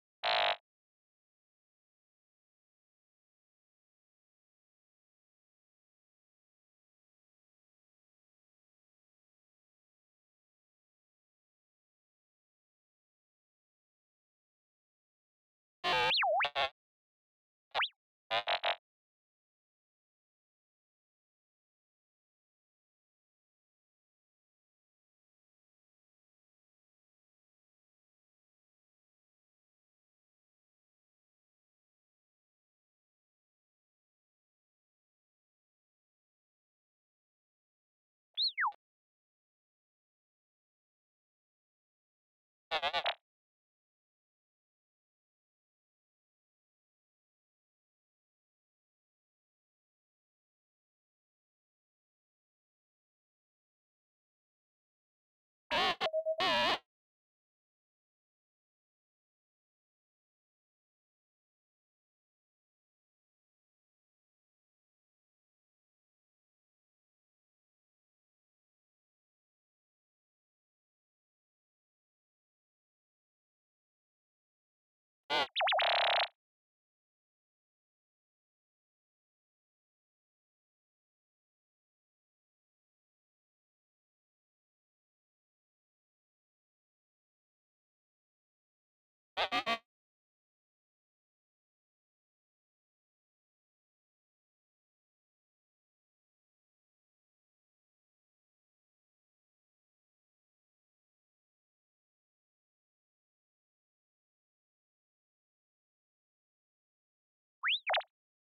cicero droid sound.ogg